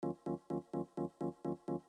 rhodes mini pont2.wav